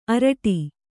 ♪ araṭi